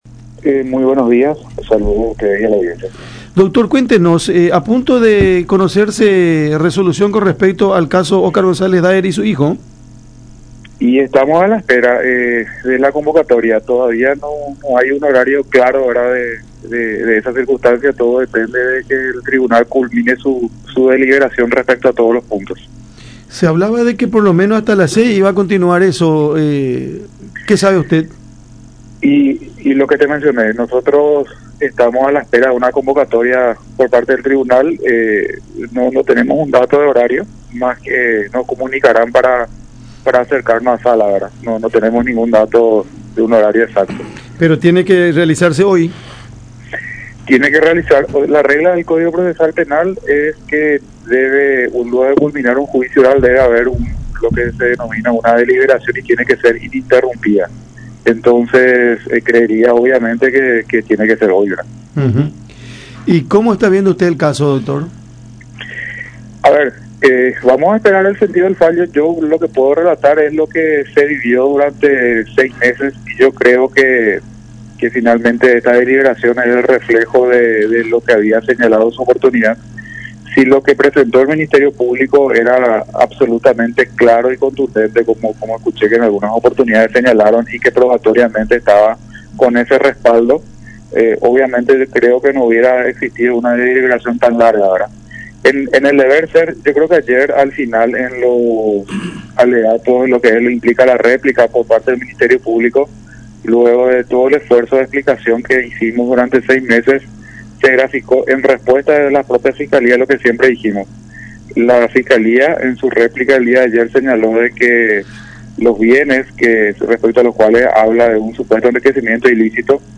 en conversación con Cada Mañana por La Unión